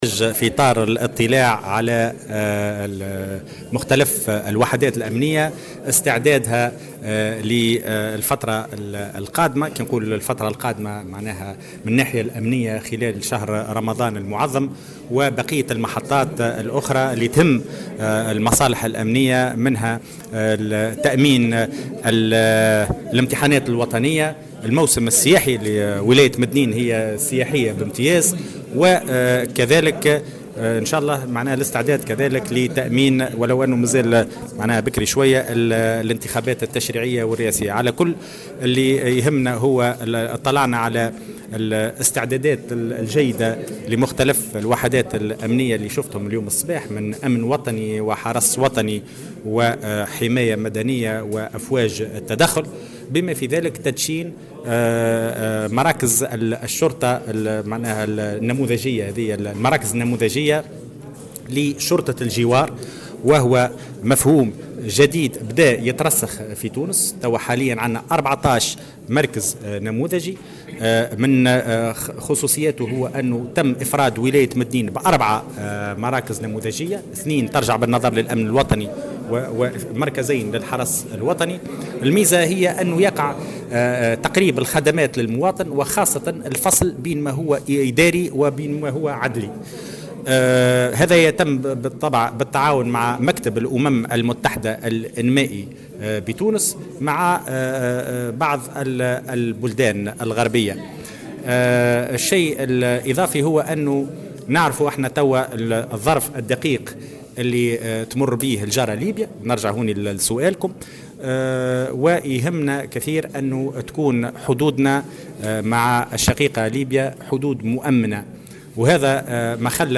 و أكّد الوزير في تصريح لمراسل "الجوهرة اف ام" على ضرورة الرفع من درجة اليقظة العملياتية والأمنية بالتزامن مع التطوارت الأمنية في ليبياخاصة في ظل ما يمكن أن ينجر عنها من تداعيات، و"تتمثل أساسا في إمكانية تسلل عناصر إرهابية بطرق مختلفة ومنها اعتماد جوازات سفر مزورة".، و